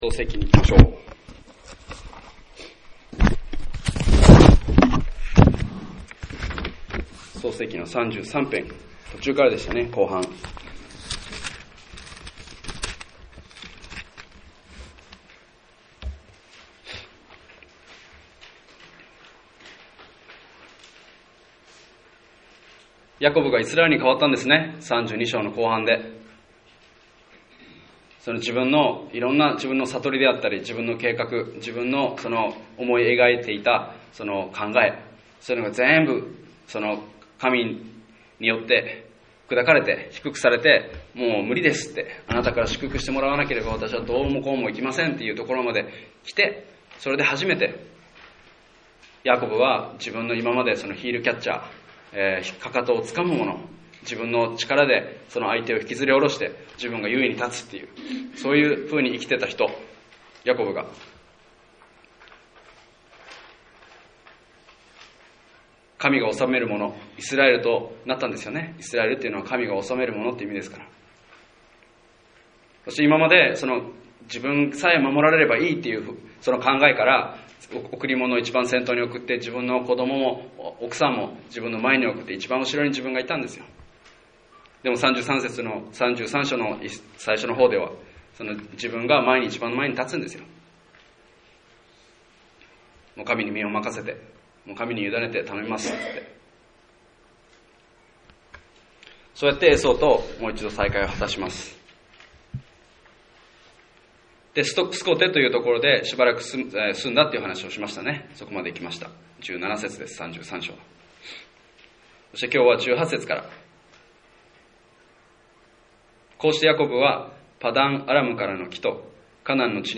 日曜礼拝：創世記
礼拝メッセージ